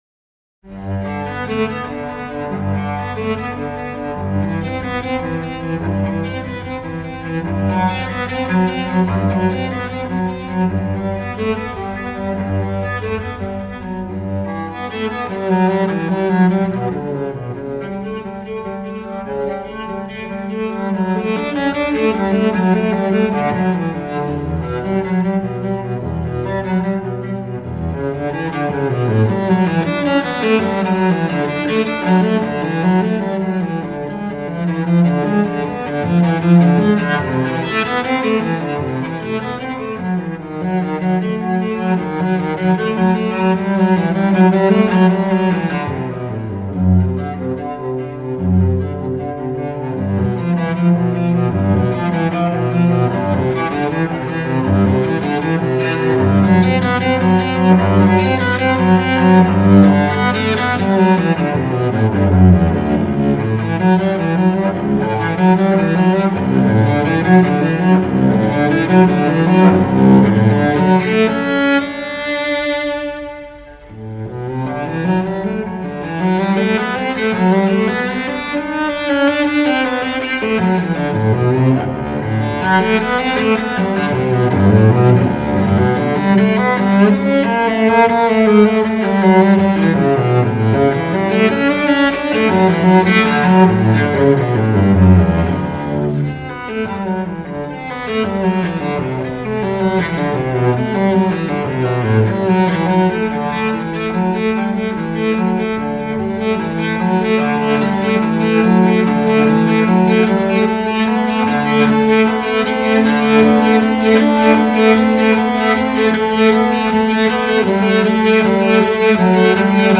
prélude de la suite numéro 1 pour violoncelle non accompagné
en sol majeur